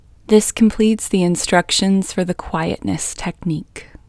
QUIETNESS Female English 23
Quietness-Female-23-1.mp3